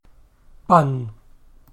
bun  /bʌn/).